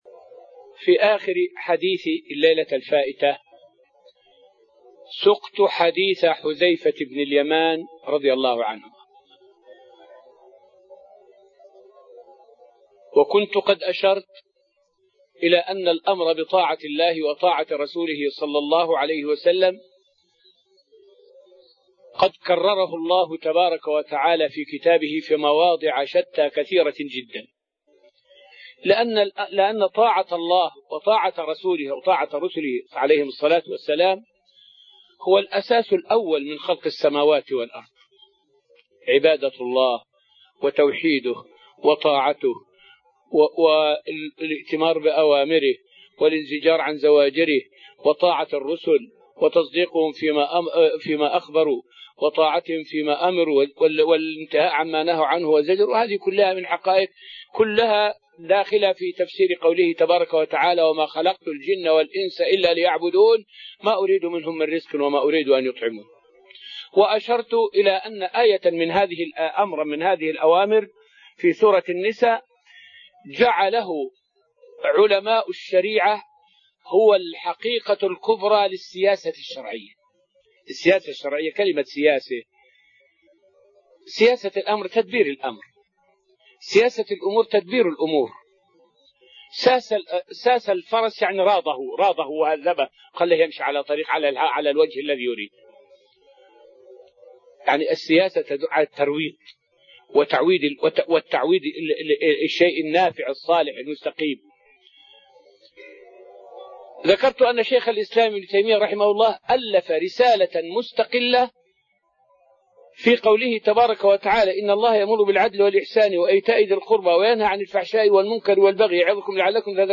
فائدة من الدرس السادس من دروس تفسير سورة الأنفال والتي ألقيت في رحاب المسجد النبوي حول وصف كعب بن زهير للصحابة وشجاعتهم.